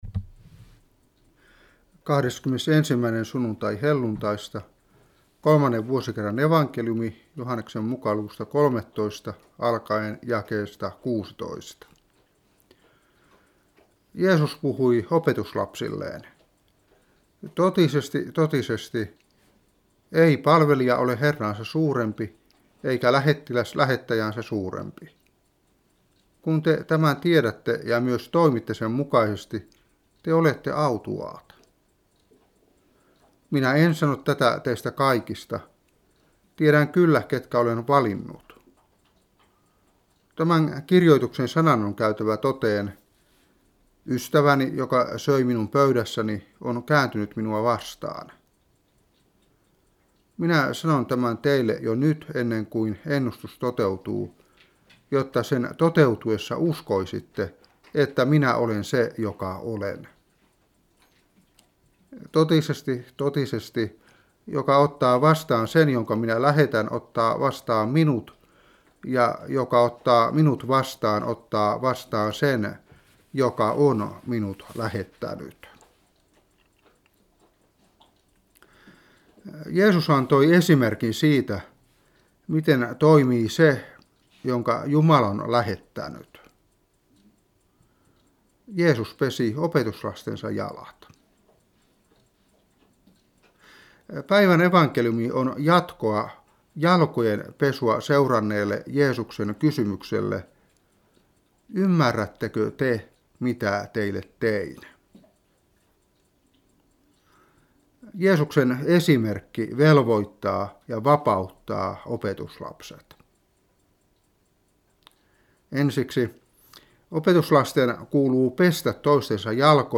Saarna 2015-10.